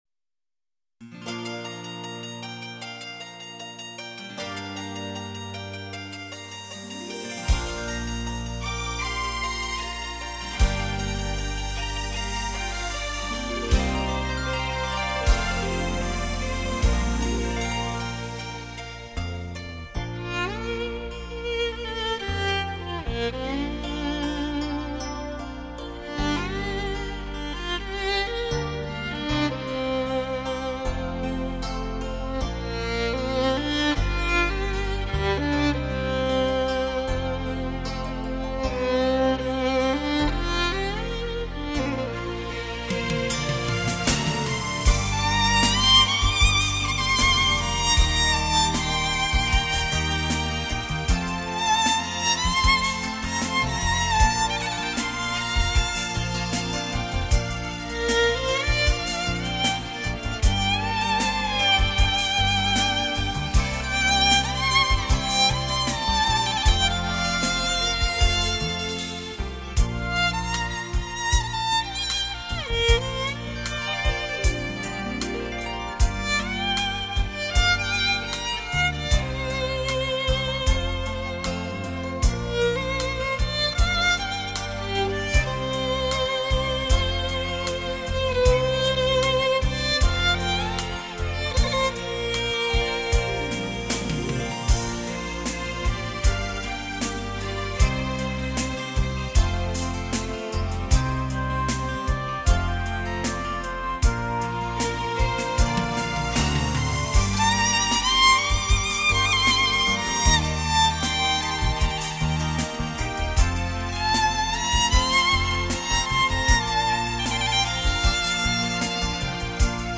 茶意如斯 清香淡远 茶乐如梦 浓香沉郁
[小提琴]